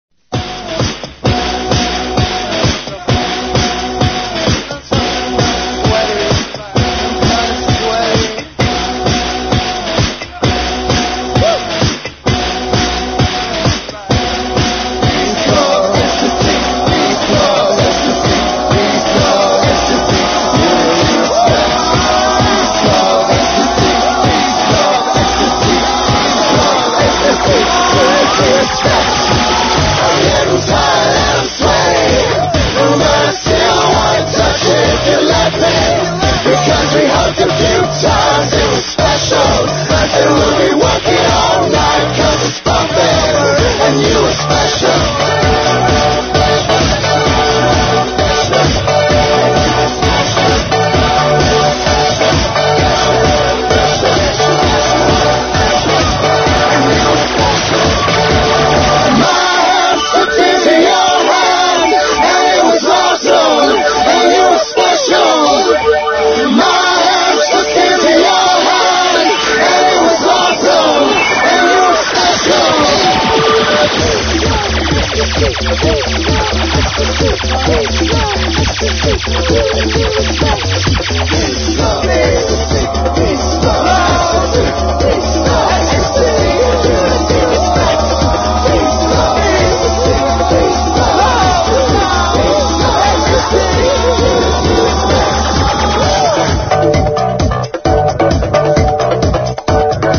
# ELECTRO